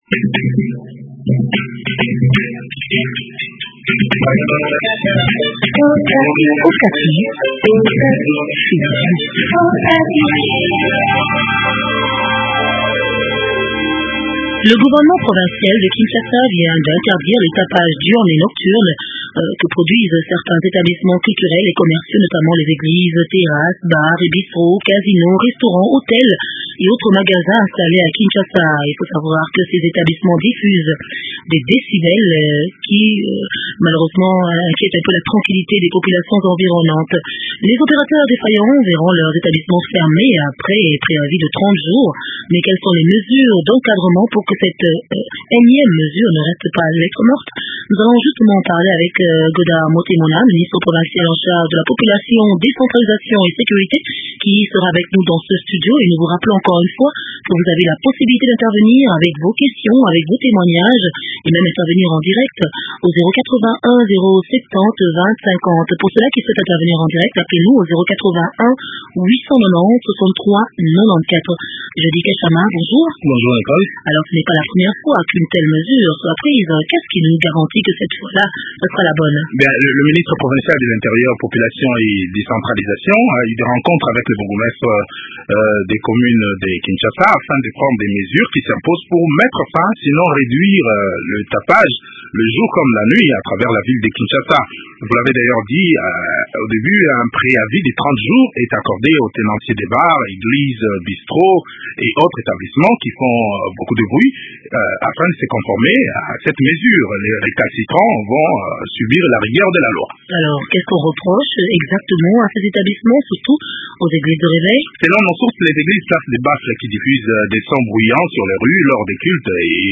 Désormais plus de tapages diurne et nocturne notamment les églises, terrasses, bars et bistrots, casinos, restaurants, hôtels et autres magasins installés à kinshasa. C’est la décision qu’a pris le gouvernement provincial de Kinshasa. Cependant quelles sont les mesures d’encadrement pour que cette enieme mesure ne reste pas lettre morte ? Des éléments de réponses dans cet entretien